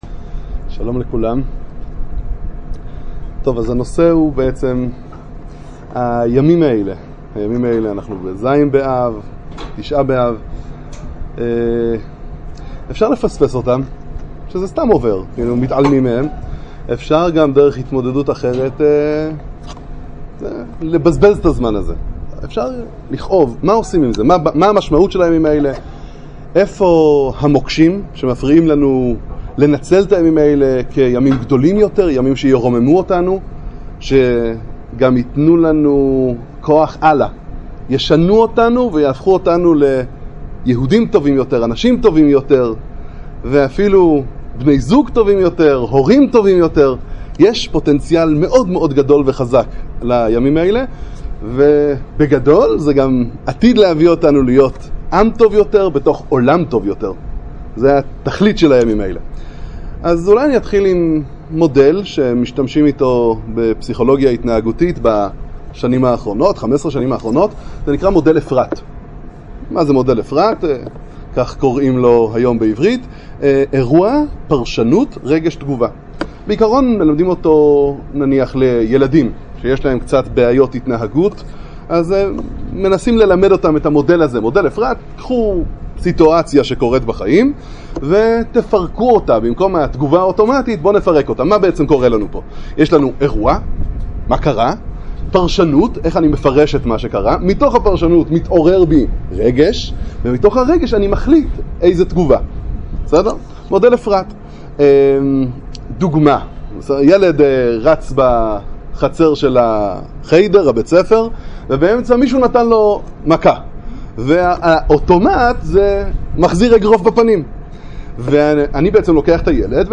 הרצאה בחברת הייטק חרדית בנושא תשעה באב